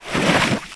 Added enter/exit sounds for swimming. 2019-07-22 15:21:57 +02:00 8.5 KiB Raw History
WtrExit1.ogg